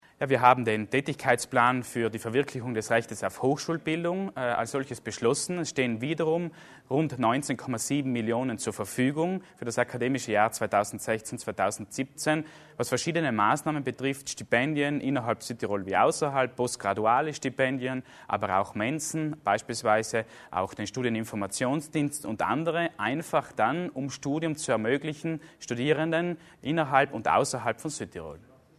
Landesrat Achammer erläutert die Initiativen zur Hochschulförderung